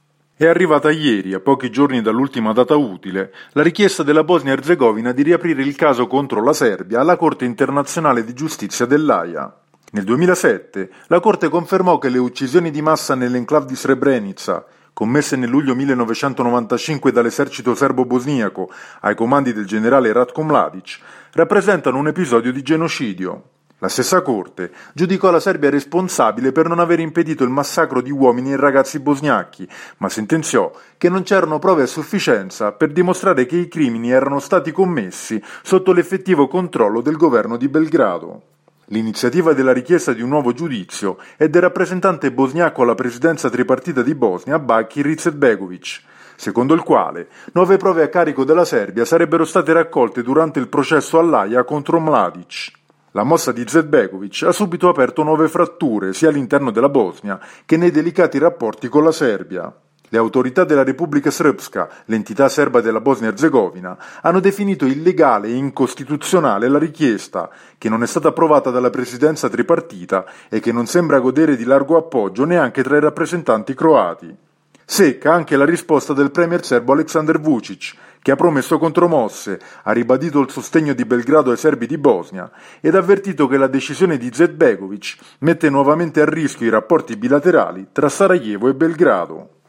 per il GR di Radio Capodistria [24 febbraio 2017]